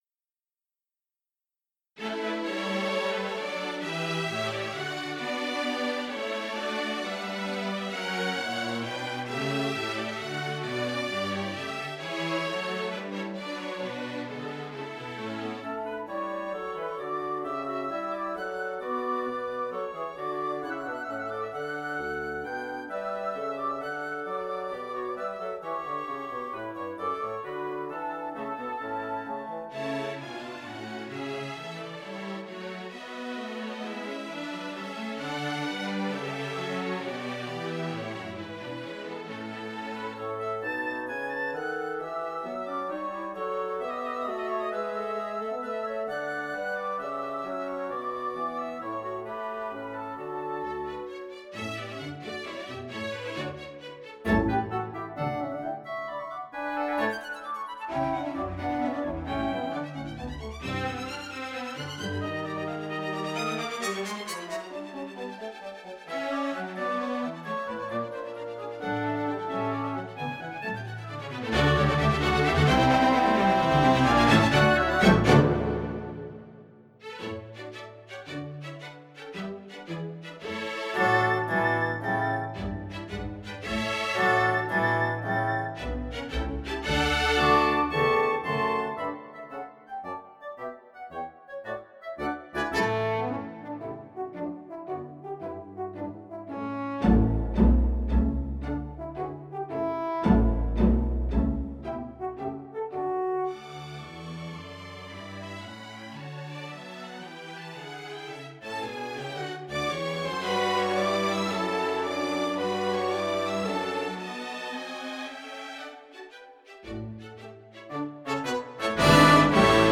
Audio rendering